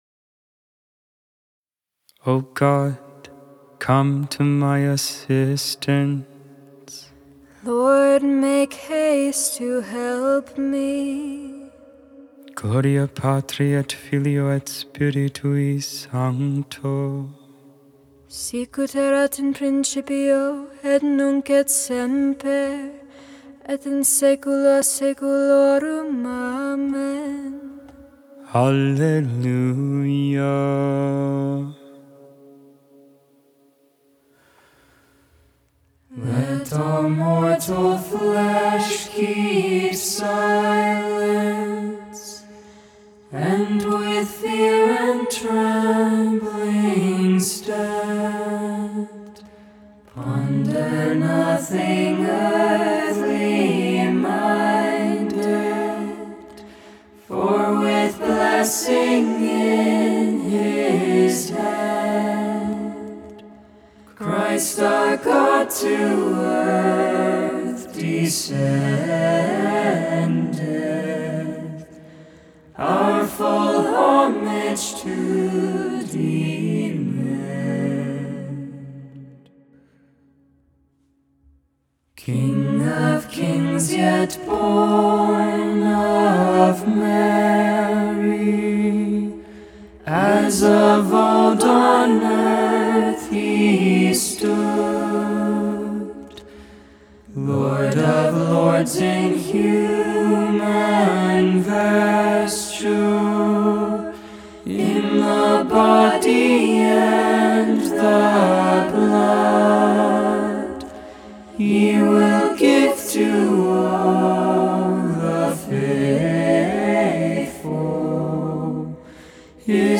1.6.24 Vespers, Saturday Evening Prayer